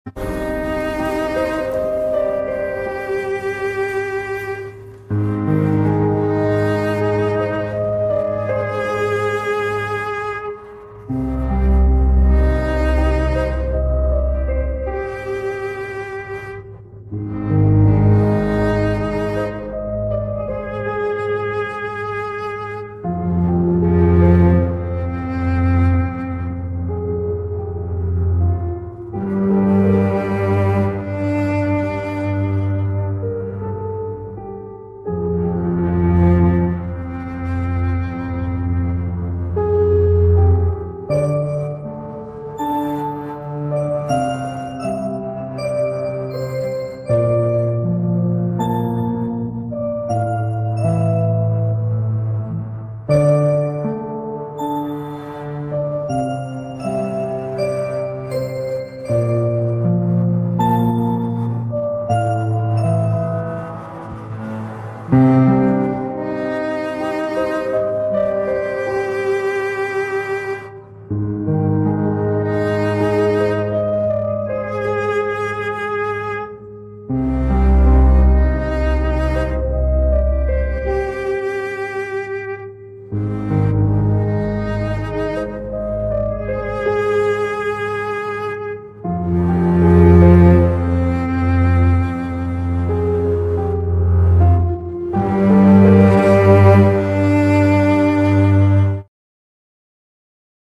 triste